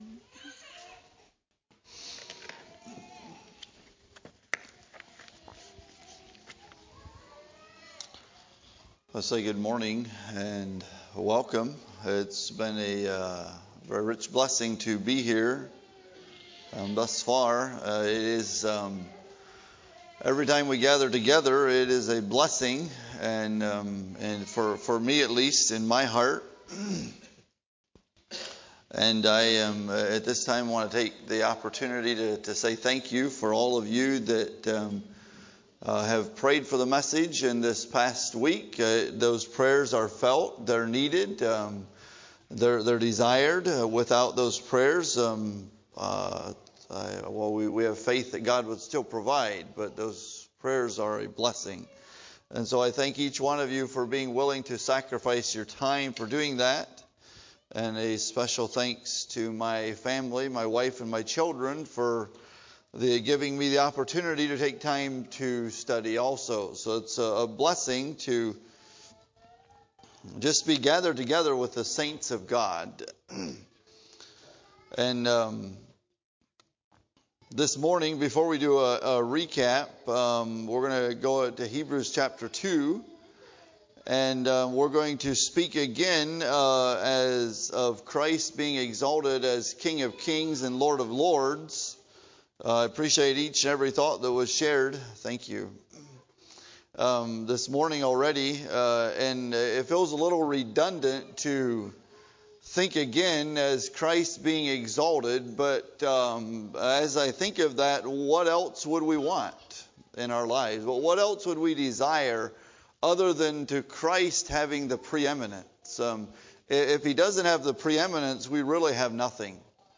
ACCF Sermons